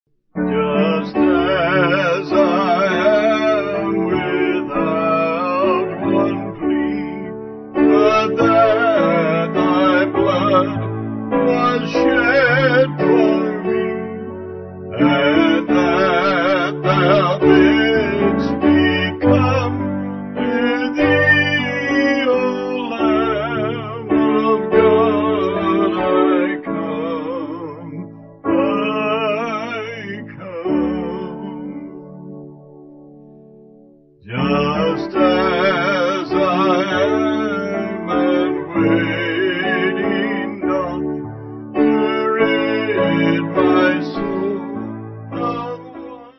Band